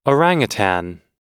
Transcription and pronunciation of the word "orangutan" in British and American variants.
orangutan_us_noun.mp3